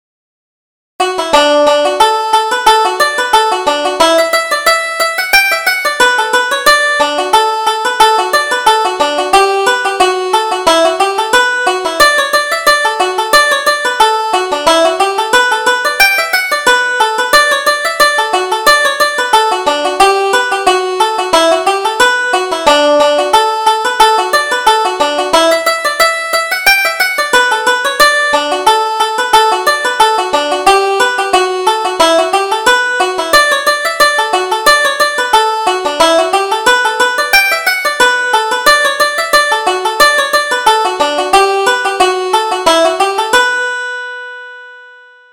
Reel: More Luck to Us